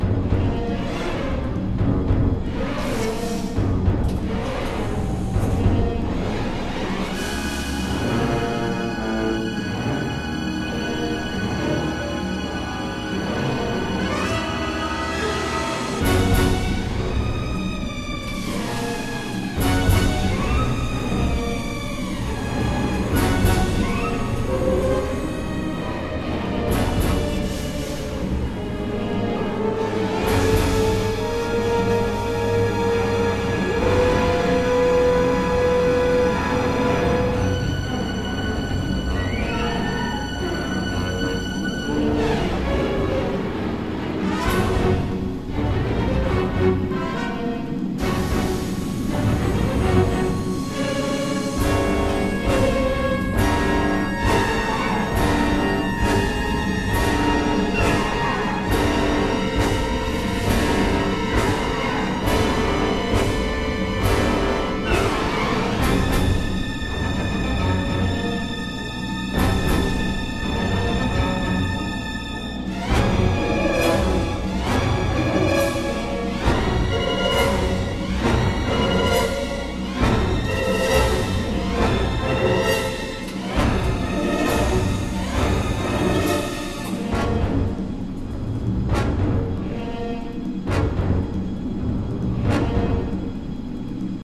Low quality samples from the game XA music files: